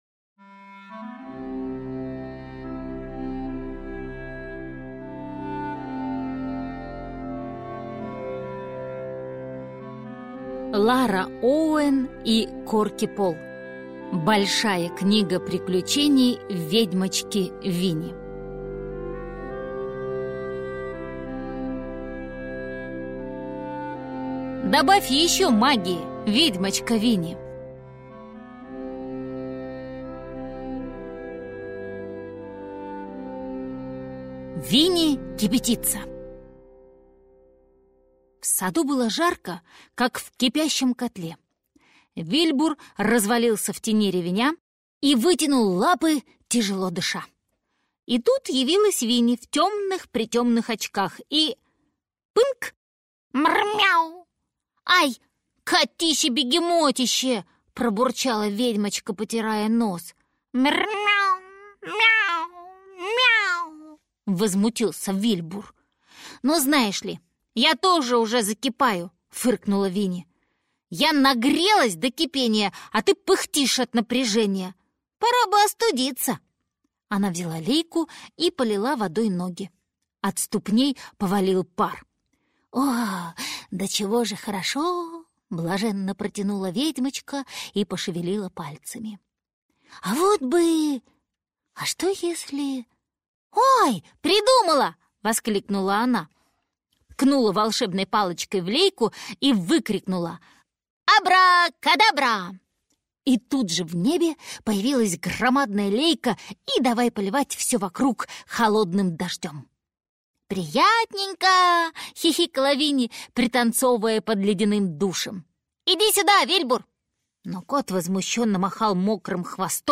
Аудиокнига Большая книга приключений ведьмочки Винни | Библиотека аудиокниг